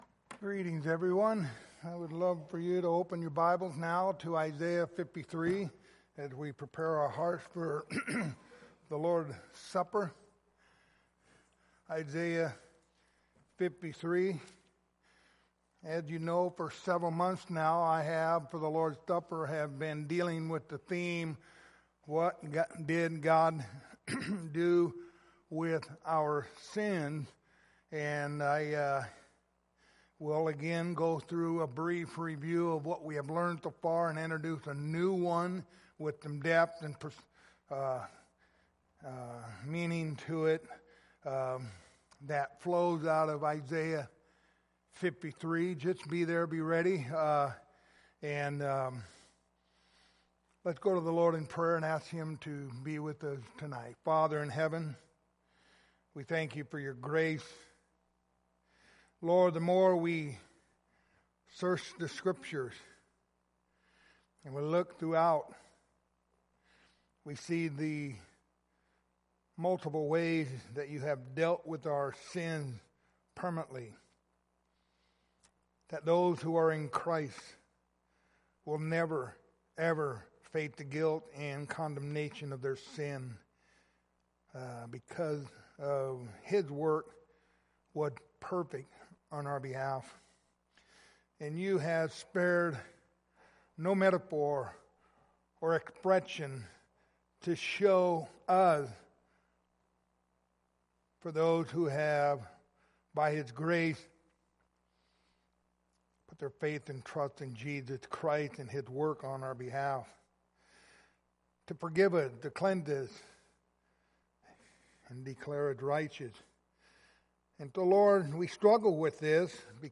Lord's Supper Passage: Isaiah 53:3-6 Service Type: Lord's Supper Topics